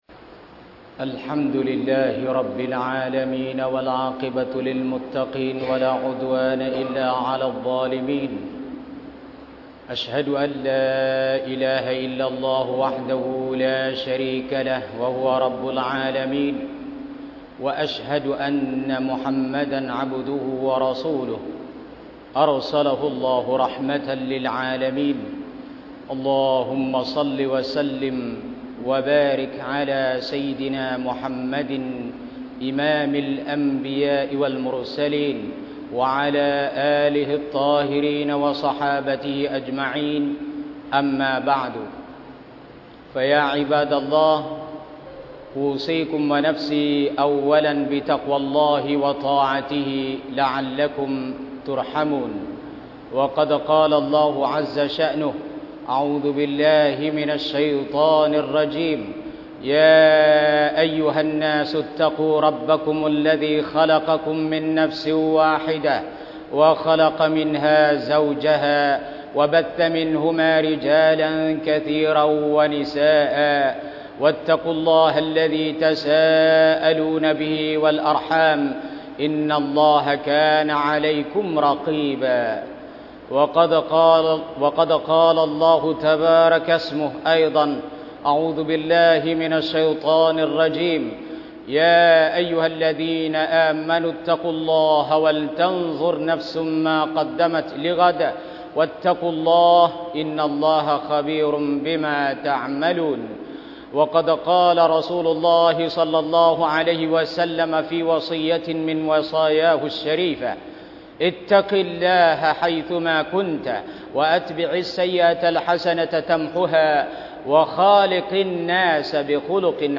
มัสยิดนูรุ้ลอิสลาม (บ้านป่า) ดาวน์โหลดไฟล์เสียง
คุตบะฮฺวันศุกร์ : ความตายคือสิ่งที่แน่นอน